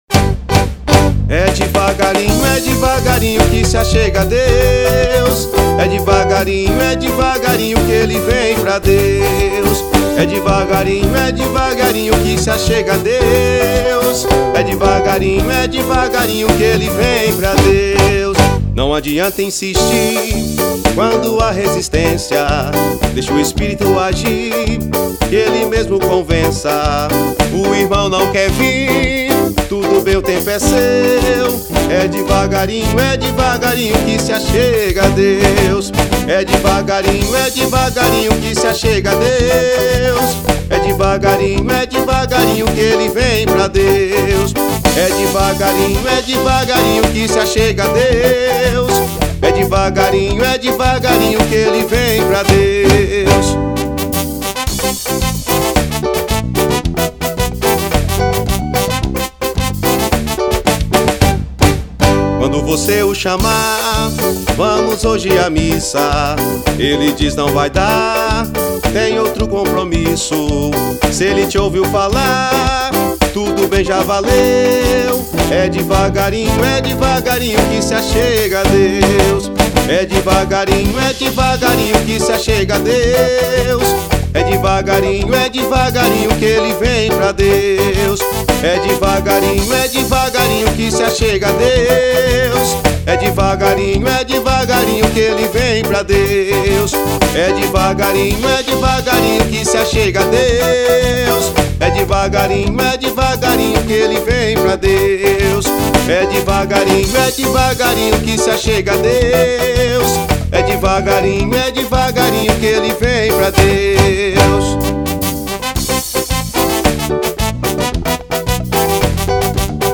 Gênero Católica.